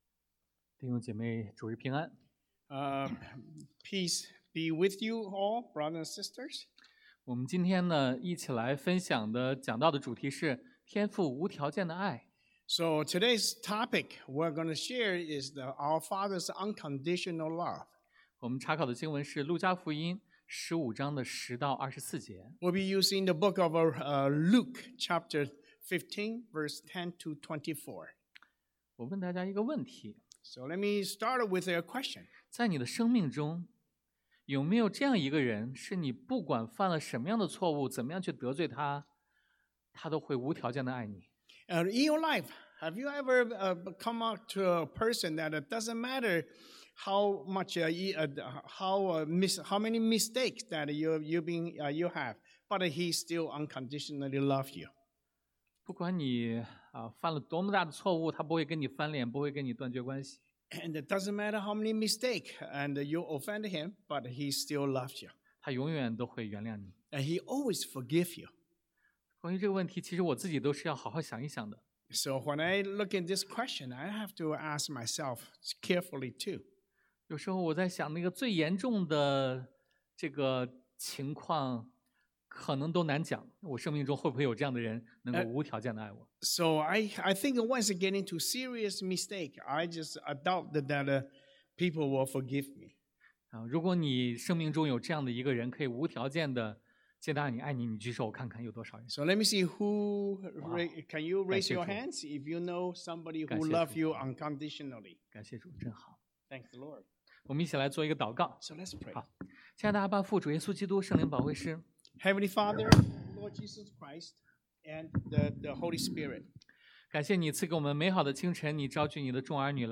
Passage: 路加福音 Luke 15:10-24 Service Type: Sunday AM